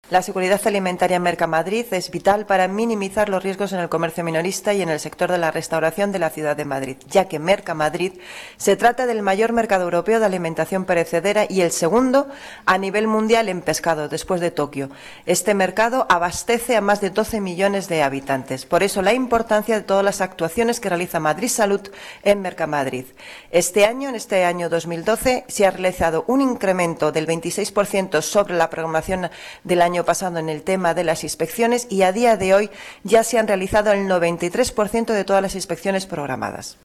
Nueva ventana:Declaraciones de Fatima Nuñez, concejal delegada de Seguridad y Emergencias